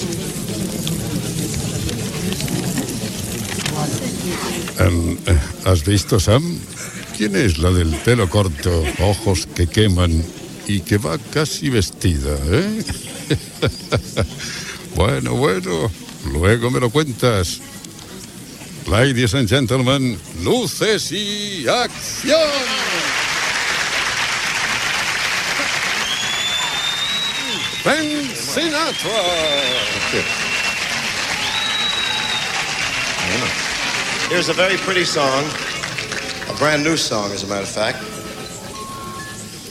Presentació d'una cançó de Frank Sinatra.
Musical
FM